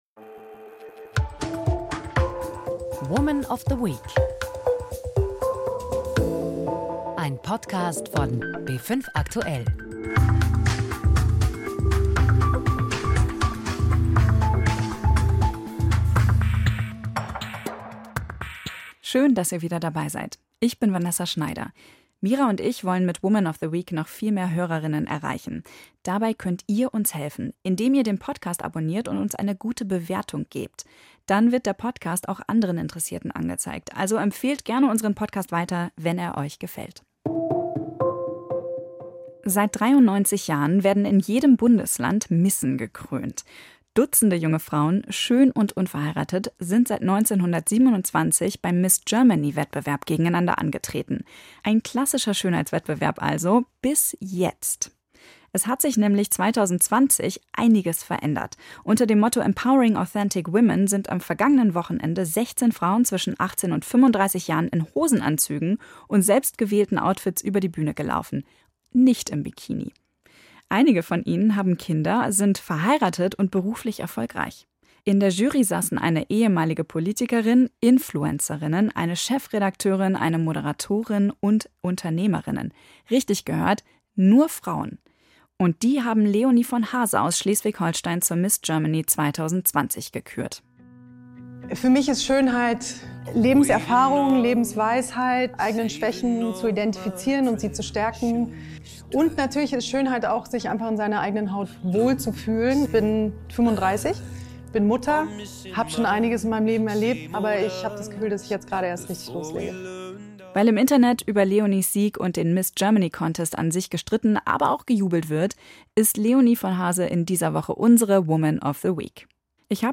In dem Interviewpodcast geht es um die aktuellen Entwicklungen dieser Woche, aber auch um den Werdegang unserer “Woman of the Week”. Natürlich gibt es auch den ein oder anderen persönlichen Tipp, konkrete Erfahrungen aus dem Berufsleben und wie sie mit Herausforderungen umgeht.